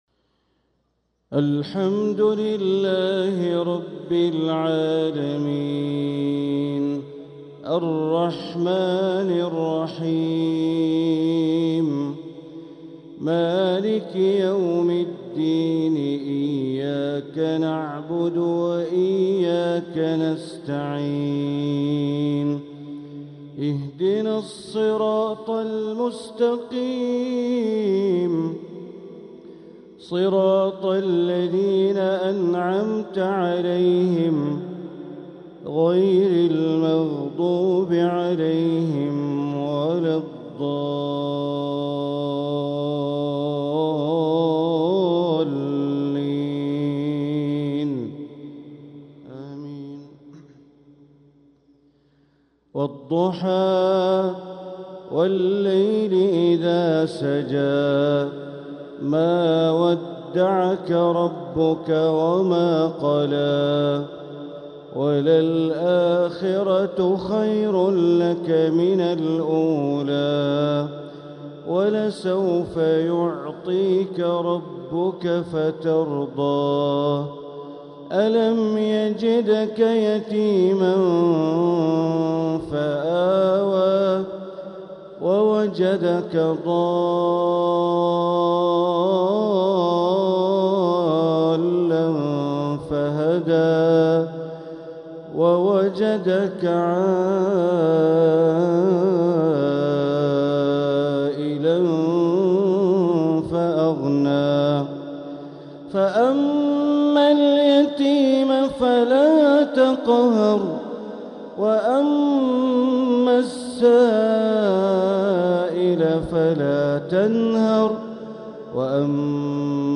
تلاوة لسورتي الضحى و الشرح | مغرب الجمعة 20 شوال 1446هـ > 1446هـ > الفروض - تلاوات بندر بليلة